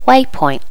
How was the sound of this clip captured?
Additional sounds, some clean up but still need to do click removal on the majority.